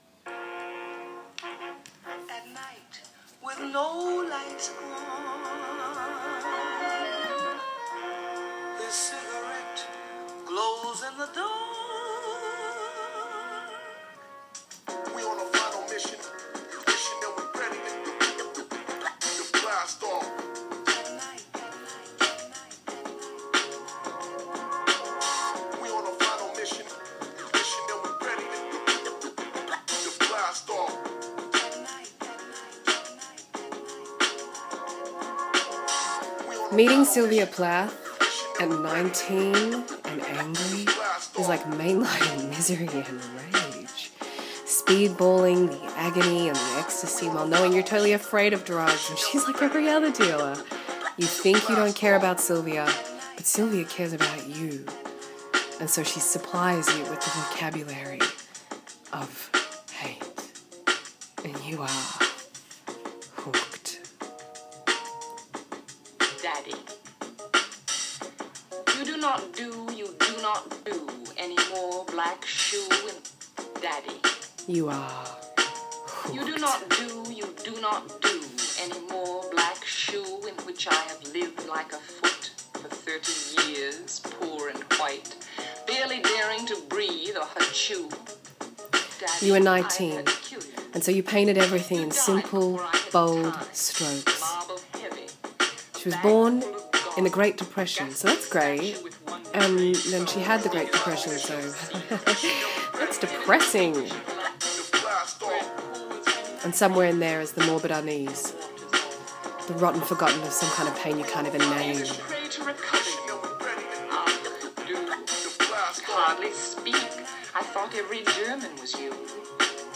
It is indeed, that’s Sylvia reading Daddy. And me pressing play on the iTunes and recording into my phone.